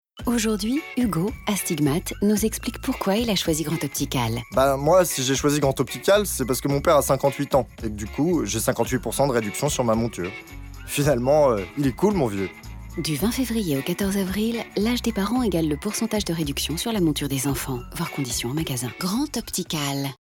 Voix off
Bande démo voix pub Grand Optical